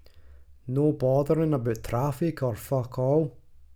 glaswegian_audio